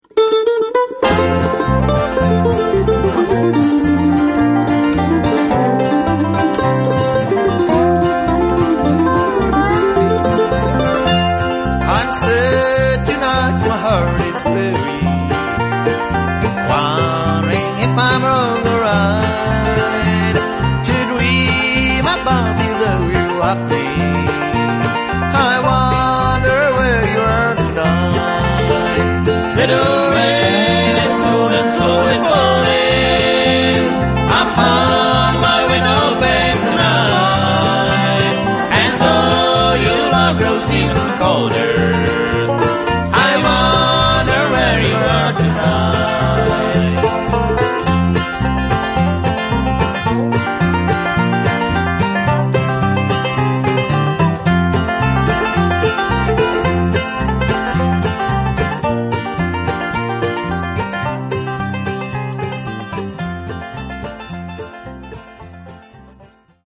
guitar, vocal
banjo, vocal
dobro, vocal